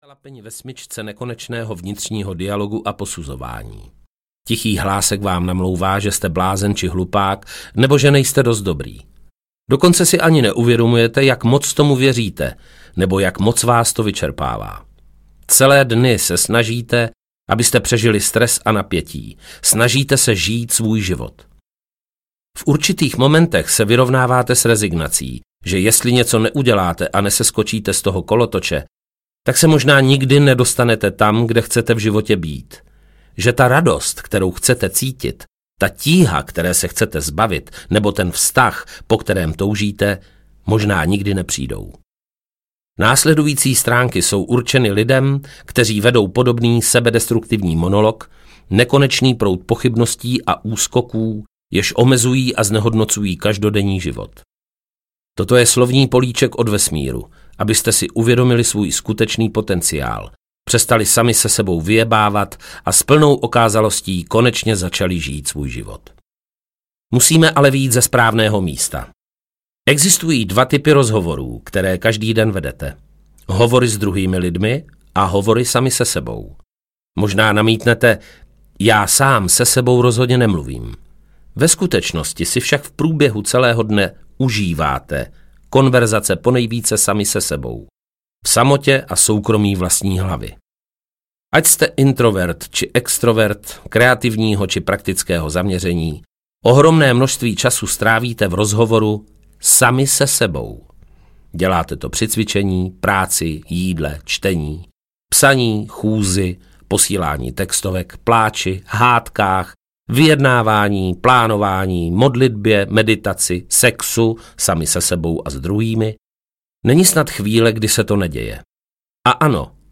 Ukázka z knihy
• InterpretPavel Nečas